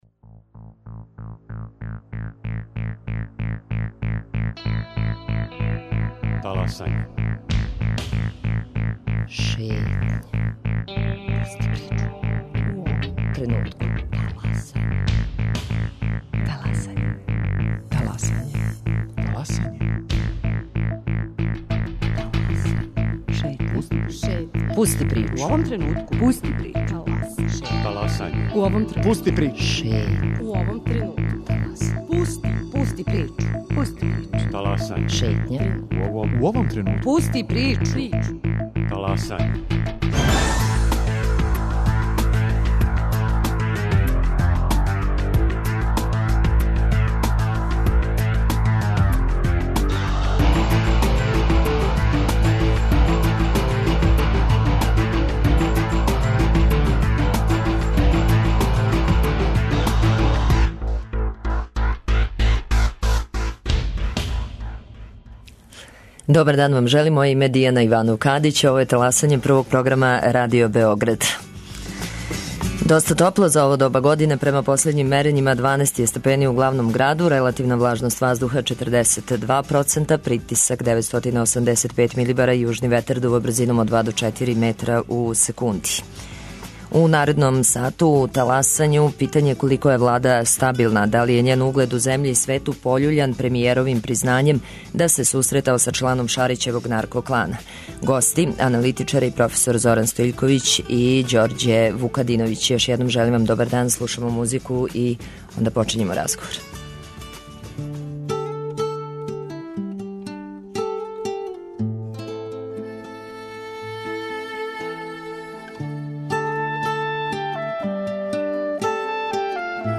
Гости: аналитичари